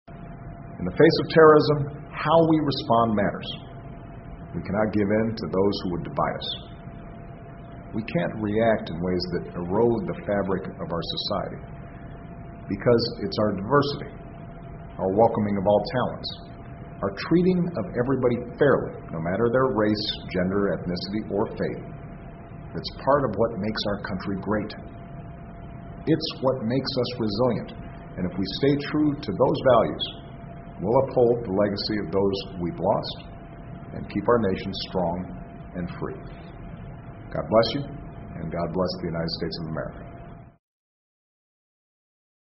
奥巴马每周电视讲话：总统呼吁继承9.11曾经失去的财富（03） 听力文件下载—在线英语听力室